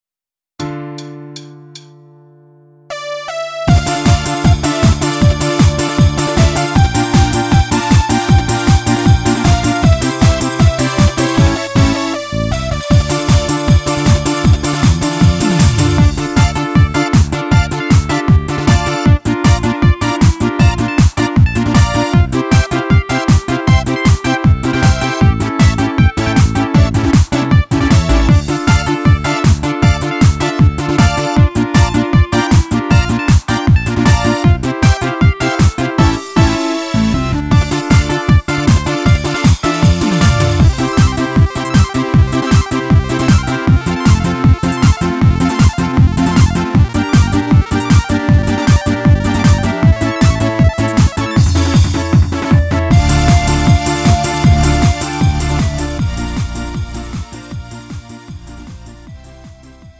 음정 원키 3:37
장르 구분 Lite MR